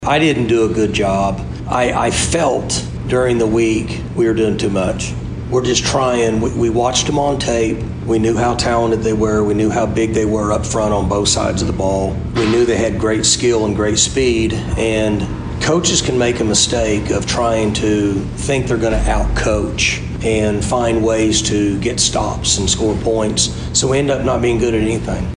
Head coach Mike Gundy talked about the loss in the postgame.
Gundy Postgame 9-8.mp3